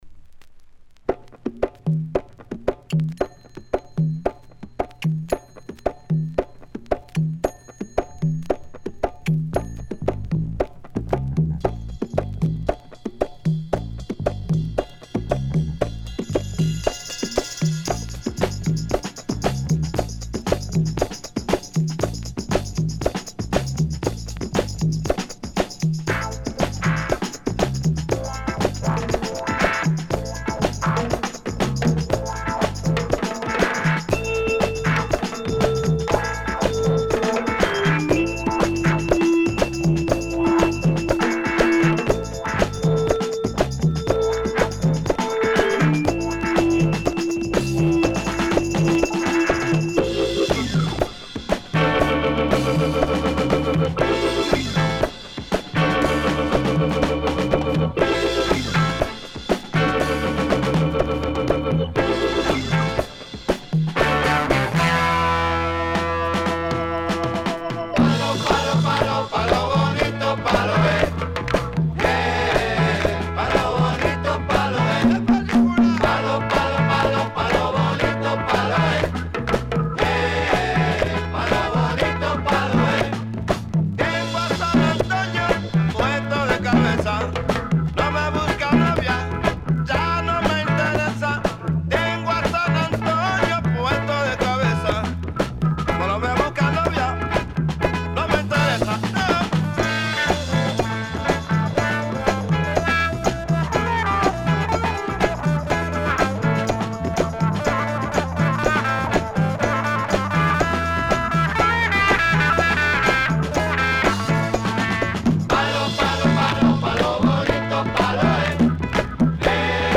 a few great bubble-gum flavored numbers